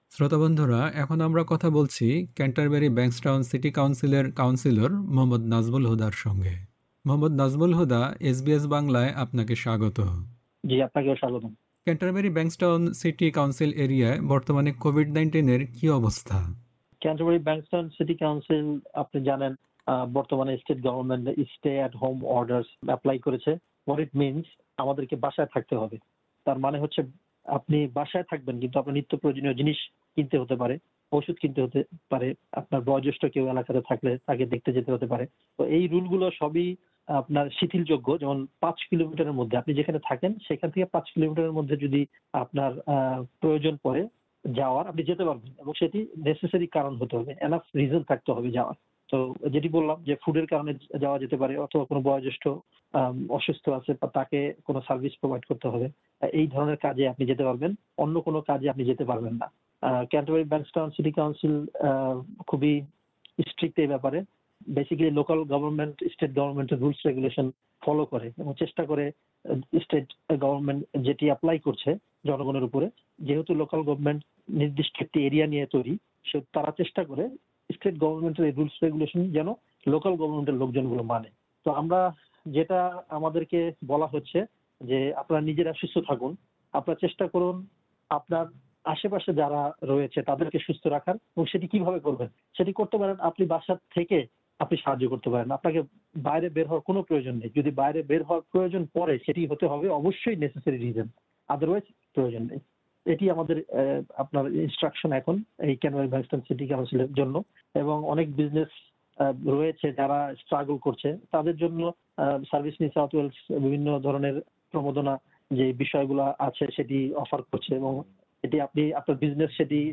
লকডাউন পরিস্থিতি নিয়ে এসবিএস বাংলার সঙ্গে কথা বলেছেন সিডনির ক্যান্টারবেরি-ব্যাংকসটাউন সিটি কাউন্সিলের বাংলাভাষী কাউন্সিলর মোহাম্মদ নাজমুল হুদা।
Source: NSW Government কাউন্সিলর মোহাম্মদ নাজমুল হুদার সাক্ষাৎকারটি শুনতে উপরের অডিও-প্লেয়ারটিতে ক্লিক করুন।